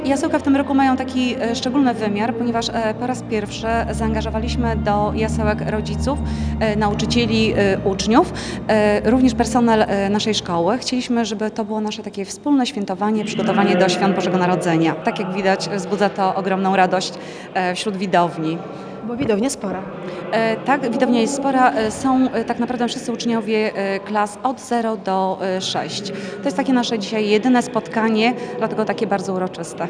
Sala widowiskowa wypełniona była po brzegi, a na widowni oprócz uczniów szkoły zasiedli również rodzice.